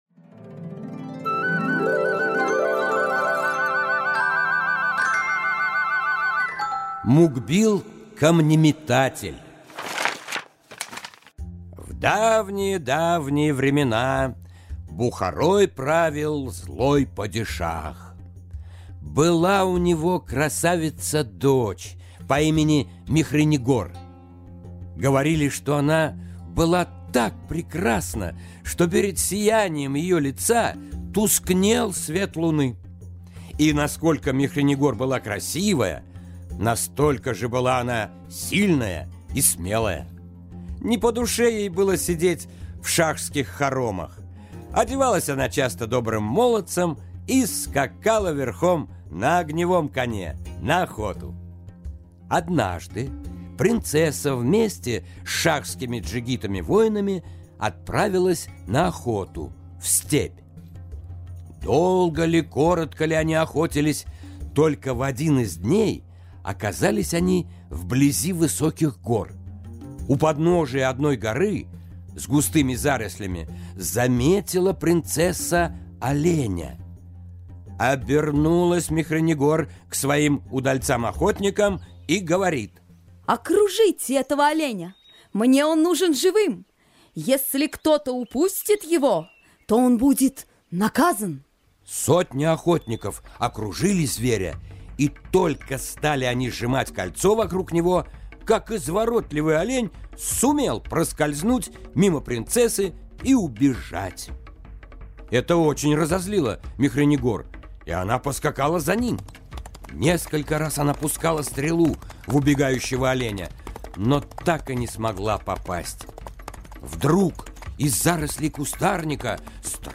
Аудиокнига Мукбил – камнеметатель